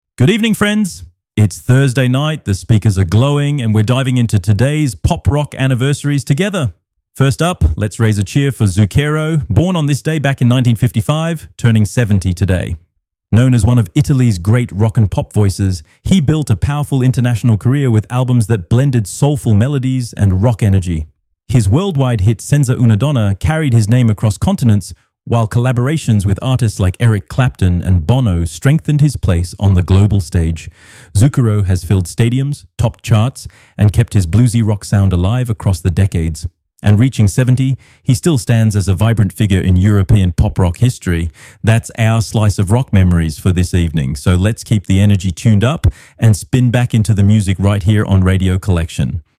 A lively column that mixes memories, anecdotes, and discoveries for a true daily journey through the history of Pop Rock fans' favorite artists, from the 70s to today.